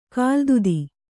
♪ kāldudi